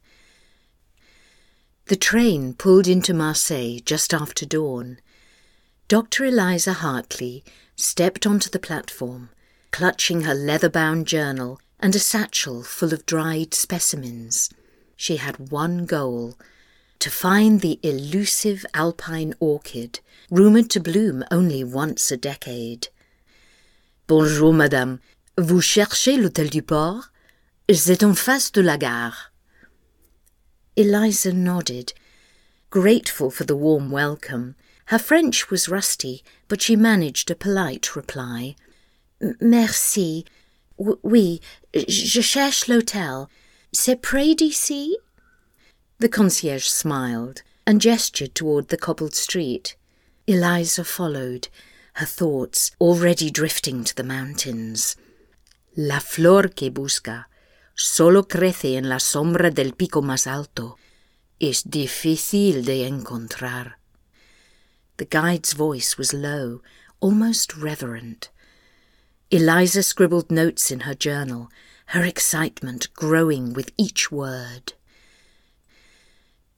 Female
Adult (30-50), Older Sound (50+)
Warm, mature, and versatile, my voice is clear and articulate with a neutral British accent.
Audiobooks
Multi-Lingual French/Spanish
1201VO_Audiobook_Demo_Narrative_CharacterFrenchSpanish.mp3